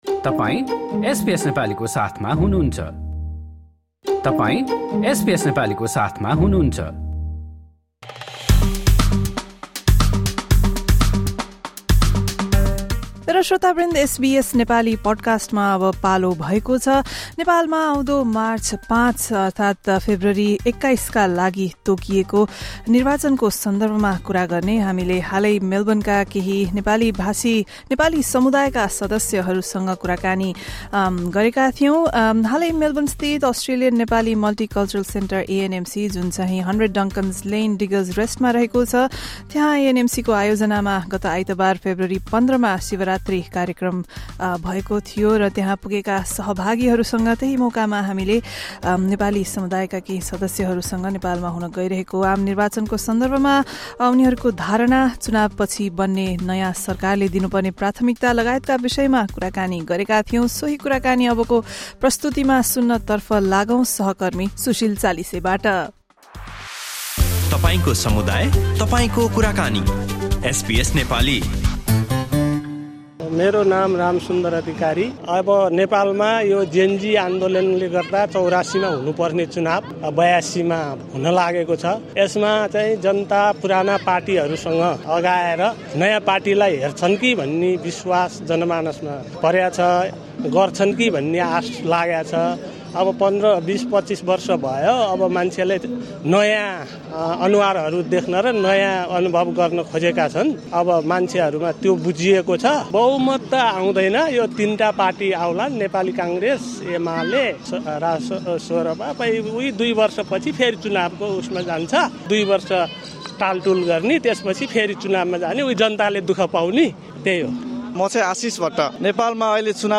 Ahead of Nepal's March 5 general elections, SBS Nepali spoke with Nepali community members in Melbourne about their views, expectations and key priorities for the polls. Listen to our conversation with community members at the Shivaratri celebration organised by the Australian Nepali Multicultural Centre (ANMC) on Sunday, February 15.